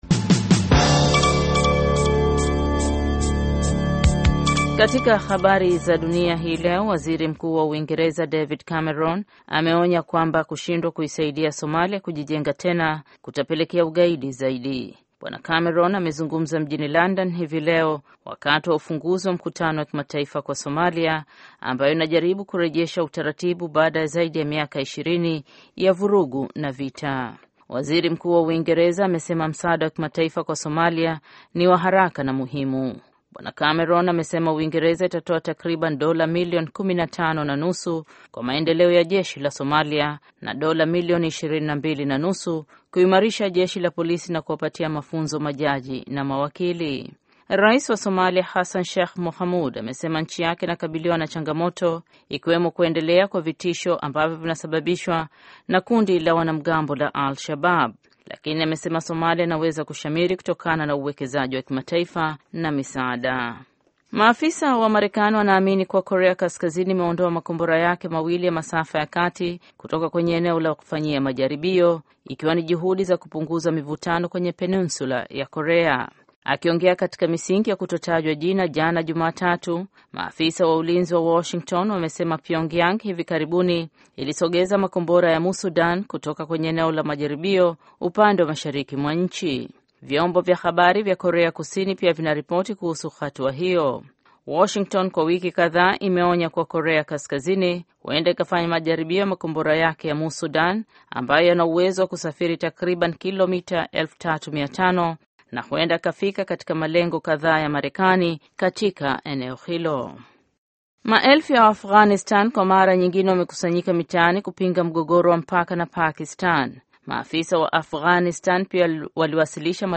Taarifa ya Habari VOA Swahili - 5:08